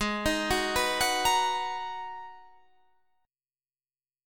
Abm9 chord